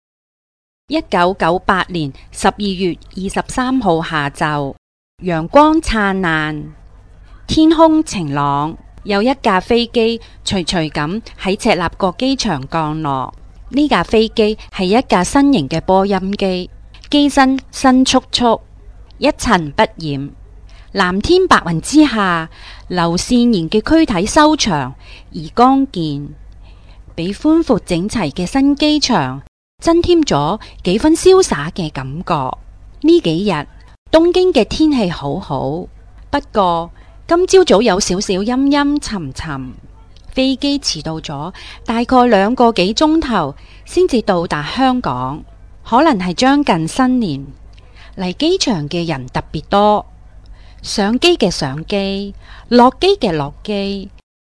中国語ナレーター・ナレーション
北京語